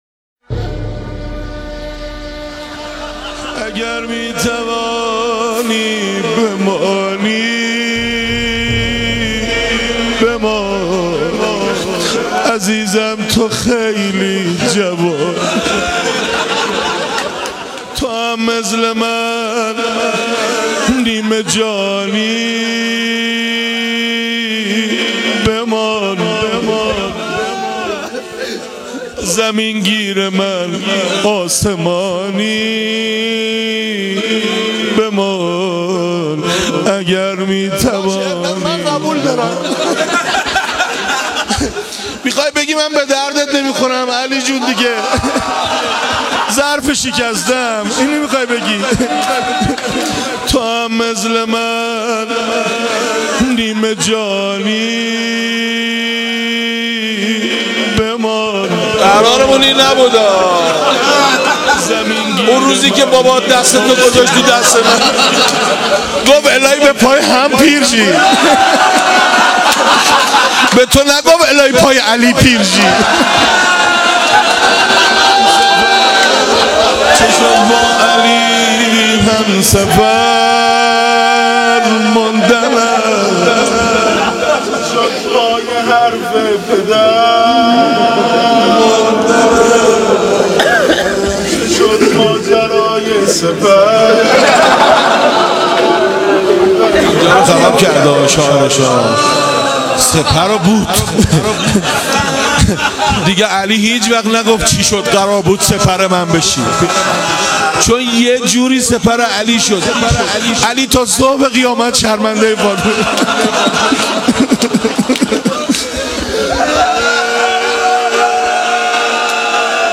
روضه خوانی
بیت الرقیه(س)-کرج